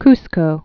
(kskō)